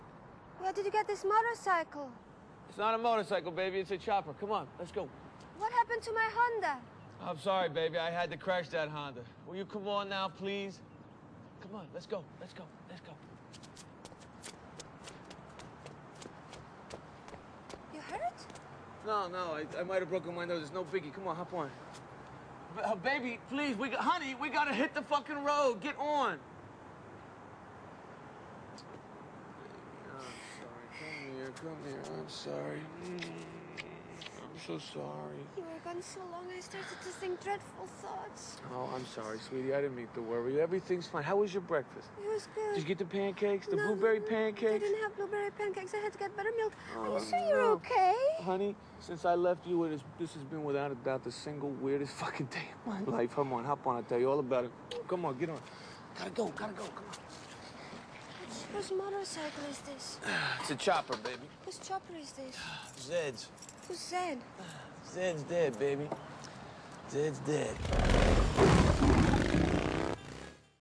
"Zed Is Dead" dialogue - Pulp Fiction